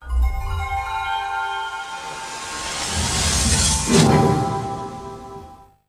Sega Saturn Startup.wav